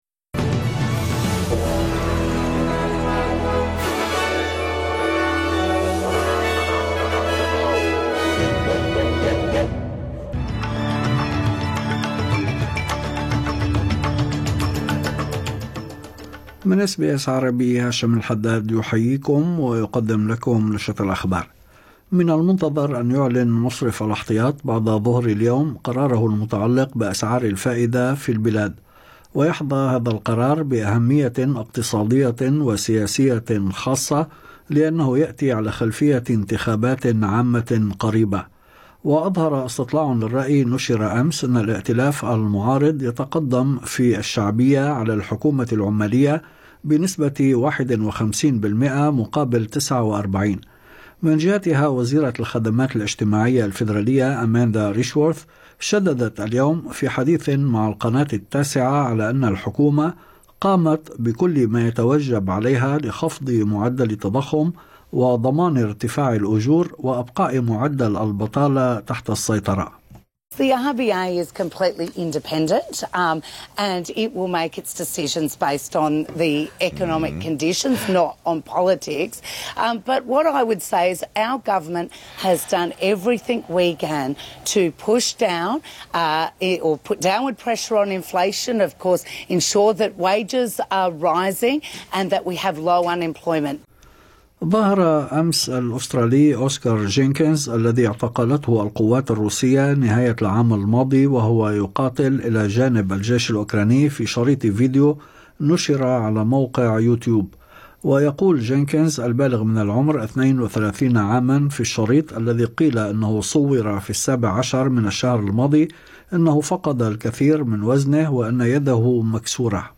نشرة أخبار الظهيرة 18/2/2025